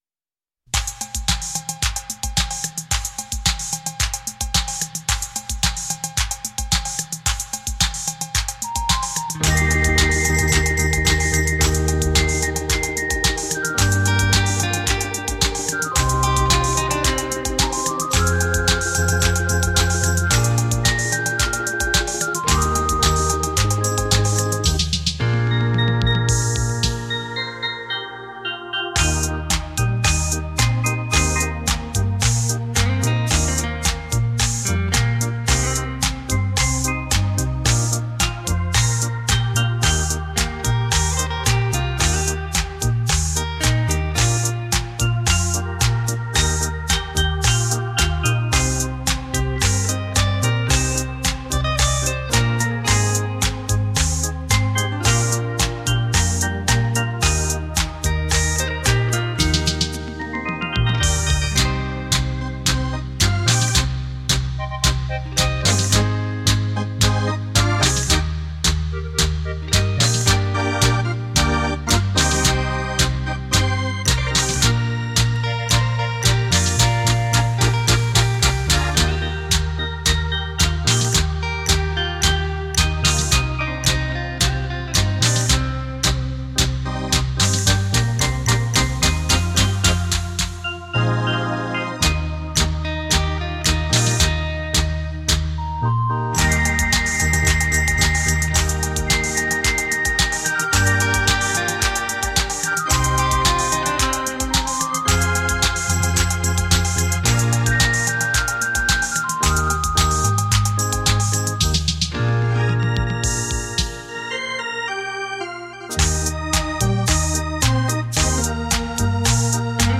电子琴与乐队鼎力合作，再创视听领域的新典范。